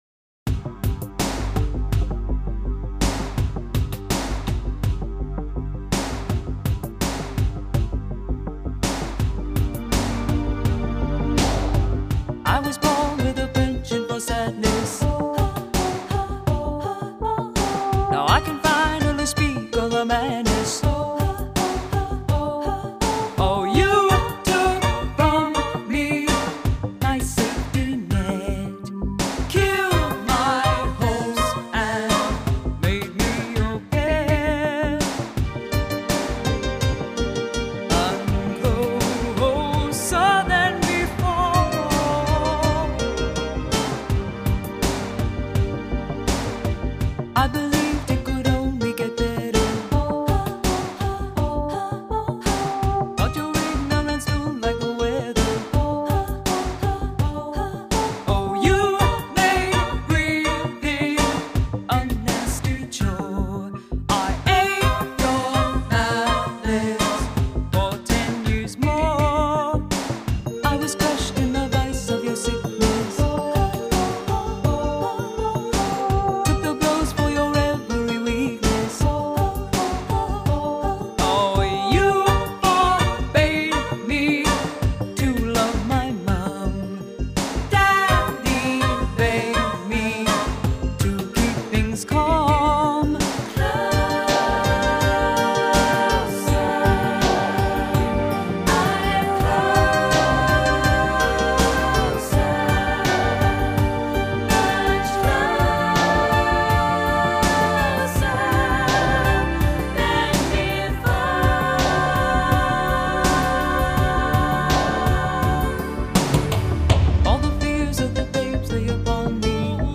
Please let them hear some female voices.